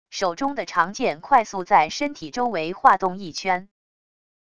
手中的长剑快速在身体周围划动一圈wav音频